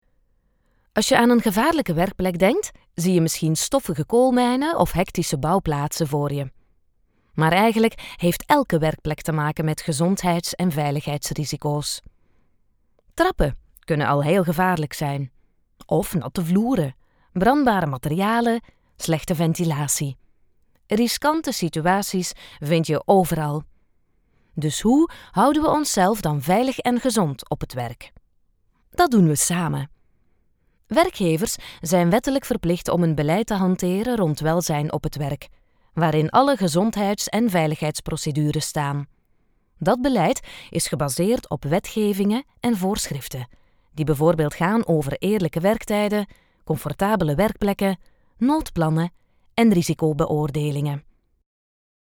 Commercial, Natural, Playful, Reliable, Warm
Explainer